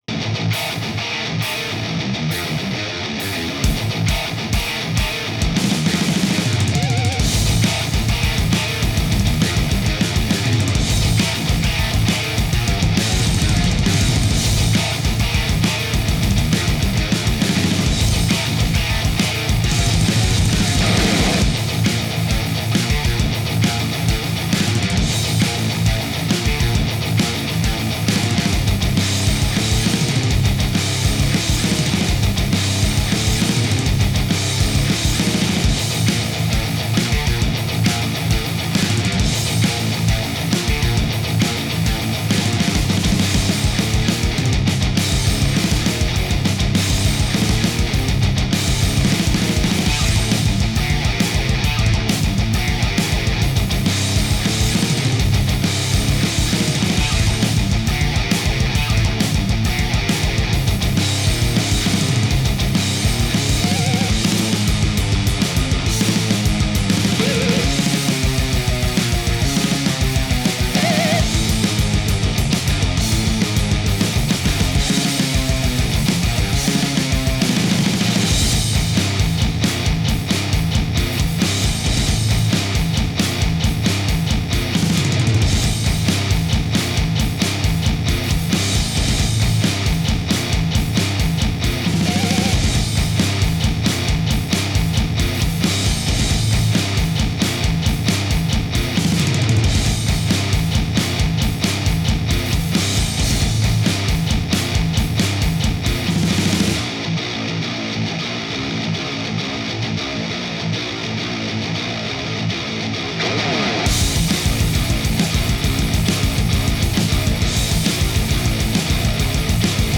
目まぐるしく展開する構成です